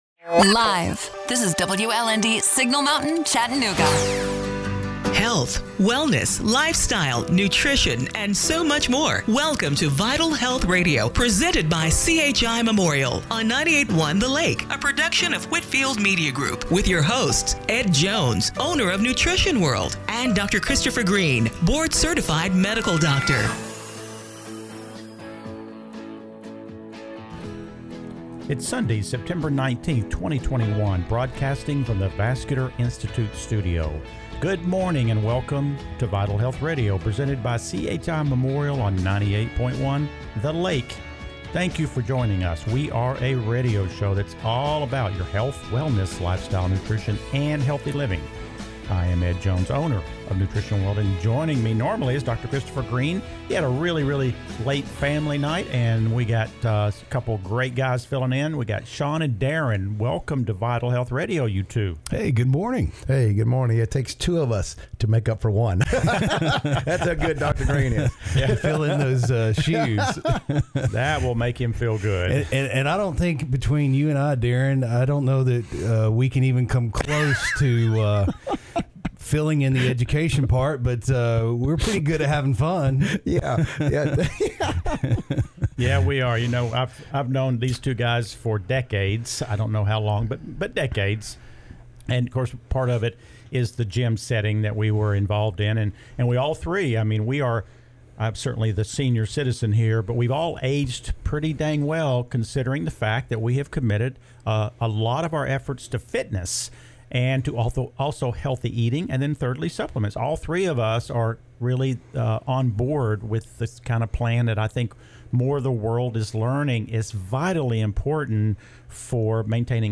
September 19, 2021 – Radio Show - Vital Health Radio